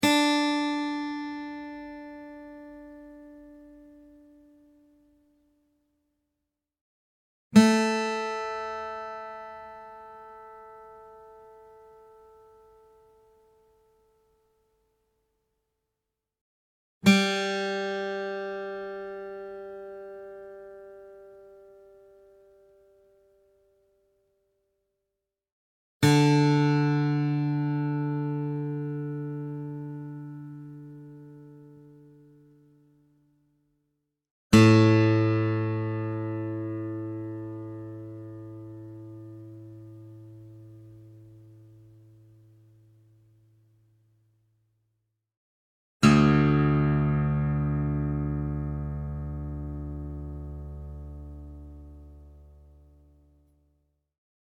Real acoustic guitar sounds in "DADGAD" Tuning
Guitar Tuning Sounds